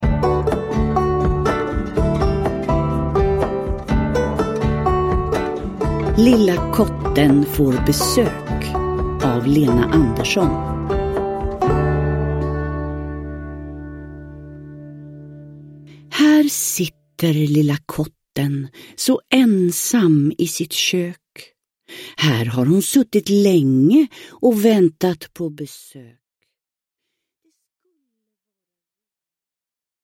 Lilla Kotten får besök – Ljudbok – Laddas ner